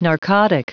Prononciation du mot narcotic en anglais (fichier audio)
Prononciation du mot : narcotic